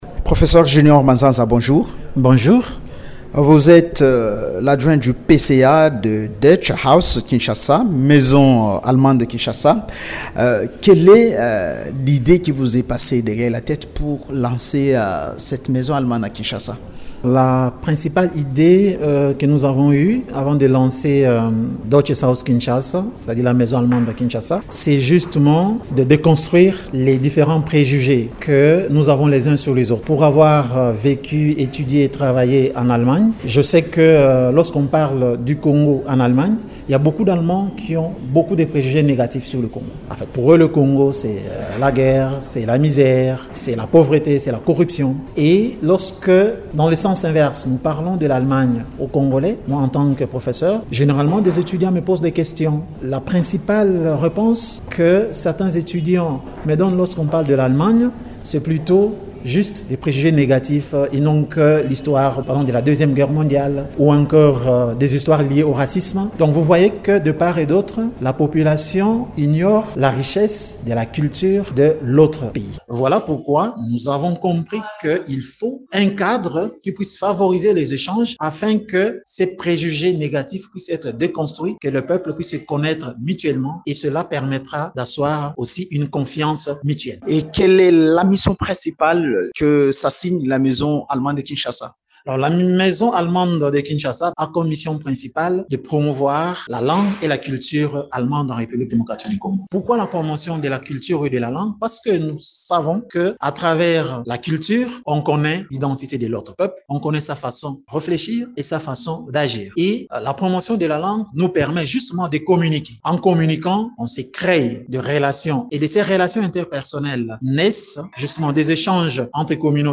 Société, Actualité, L'invité du jour, Émissions / Radio Okapi, Nouvelle grille de programmes, auditeurs, jeunes